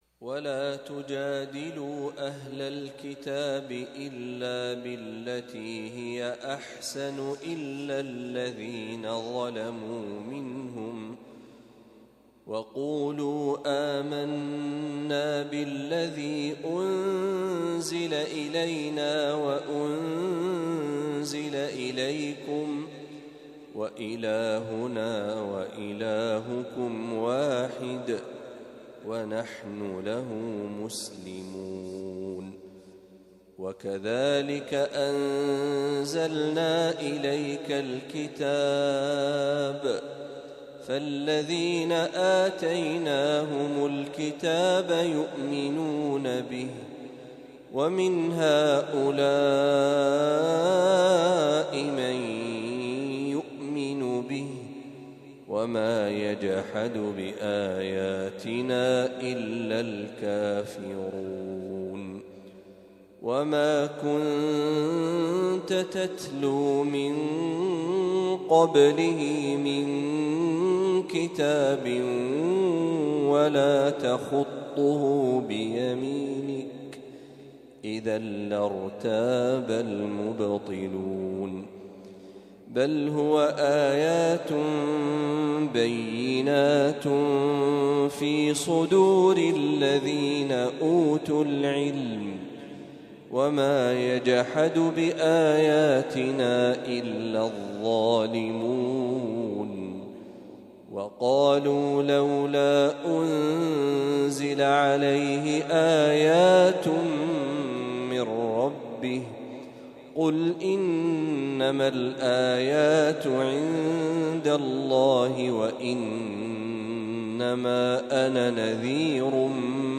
تلاوة من سورة العنكبوت | فجر الأربعاء ١١ محرم ١٤٤٦هـ > 1446هـ > تلاوات الشيخ محمد برهجي > المزيد - تلاوات الحرمين